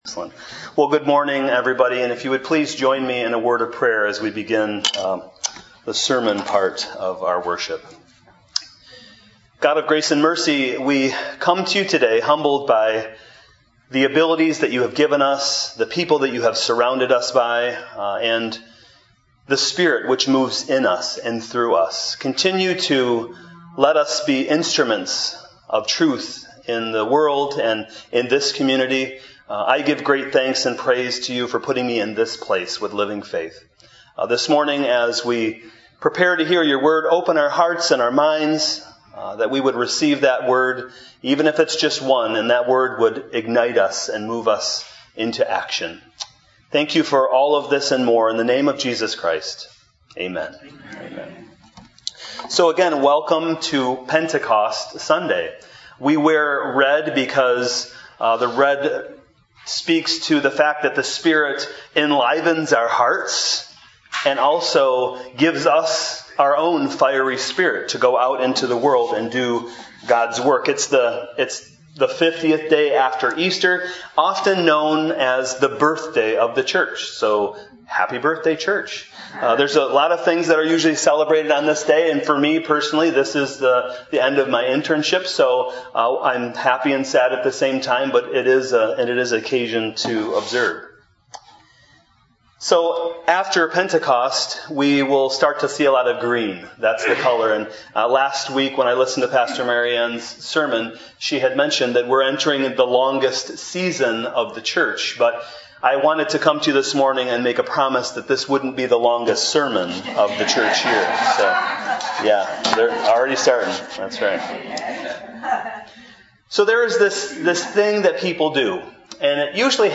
Adult Sermons